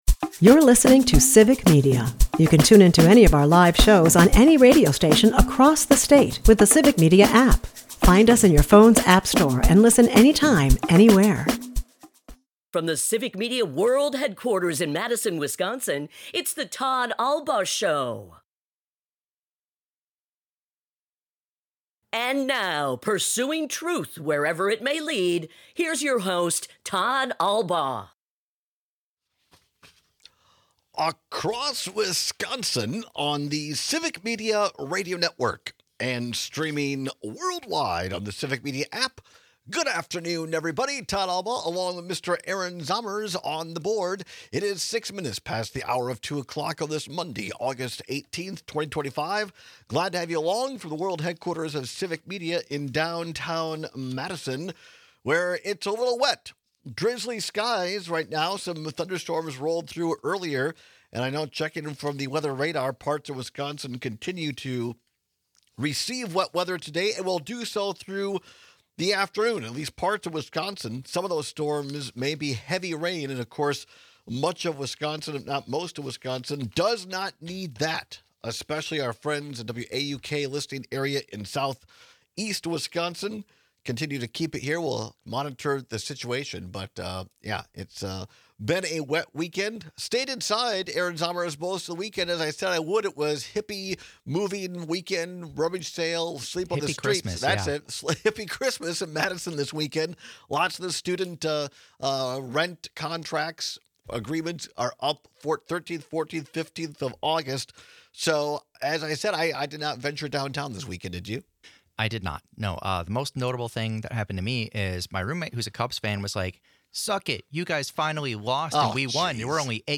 We take some of your calls on this dangerous canary in the coal mine.